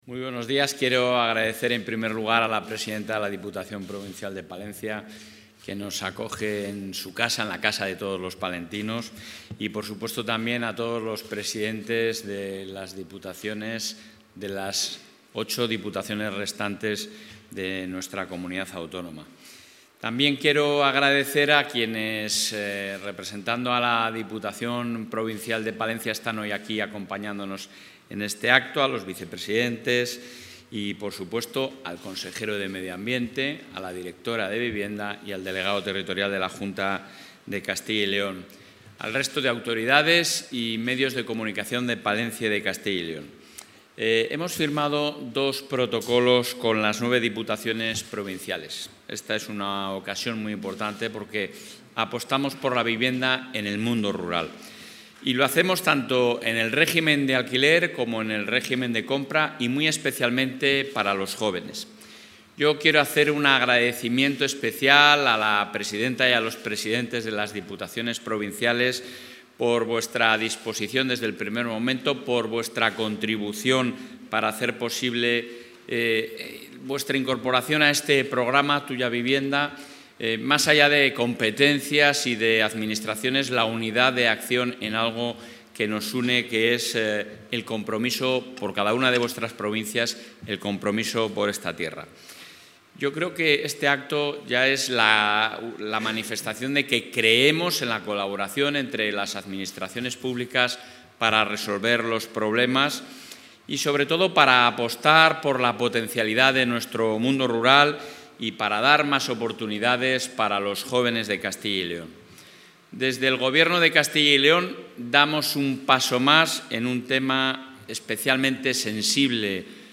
Intervención del presidente de la Junta.
El presidente de la Junta de Castilla y León, Alfonso Fernández Mañueco, y los presidentes de las nueve diputaciones provinciales, han firmado hoy en Palencia dos protocolos de colaboración en materia de vivienda rural dirigidos especialmente a los jóvenes.